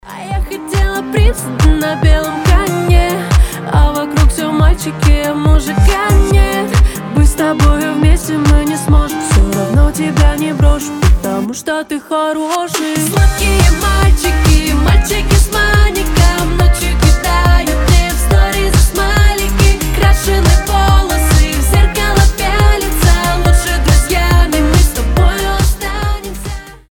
• Качество: 320, Stereo
веселые
заводные
женский голос